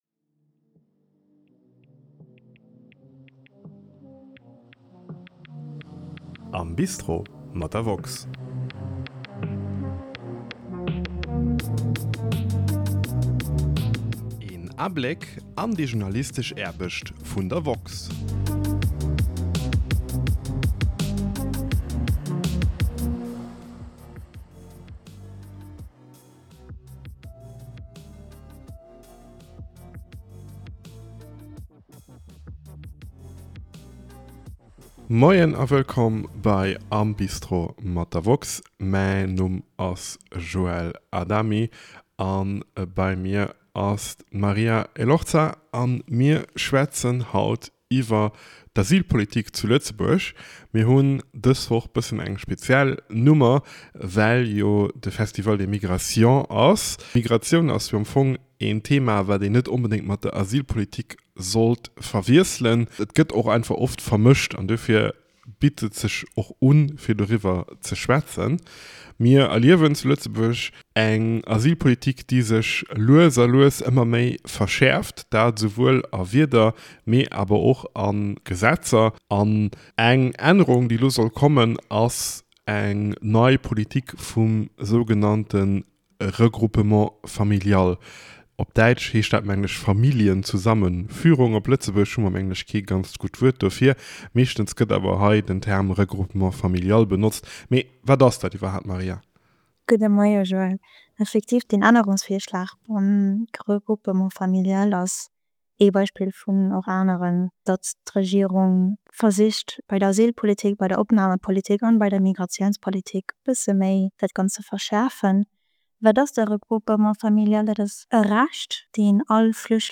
Dës Woch diskutéieren zwee Journalist*innen iwwert d’Asylpolitik zu Lëtzebuerg.